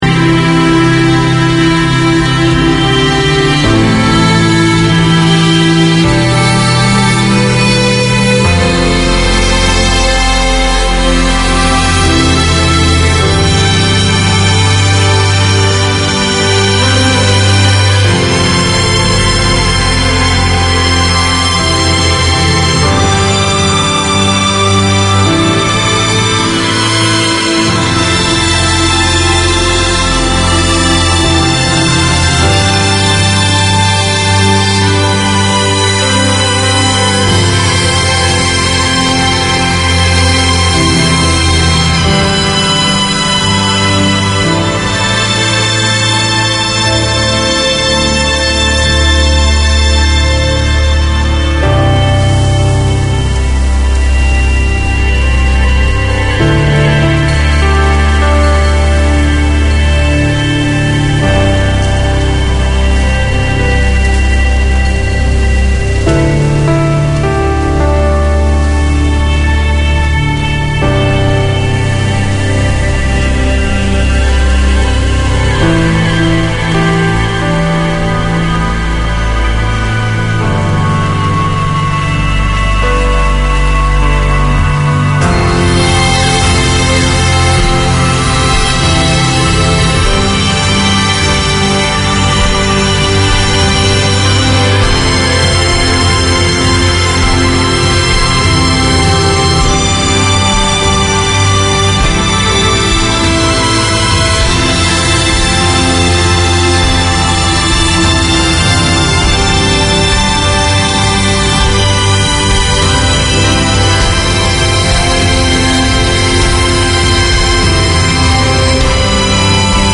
Catering to a wide community of Arabic, Syriac, Chaldean and Kurdish speakers, Voice of Mesopotamia presents an engaging and entertaining hour of radio. Tune in for interviews with both local guests and speakers abroad, a youth-led segment and music from across Mesopotamia.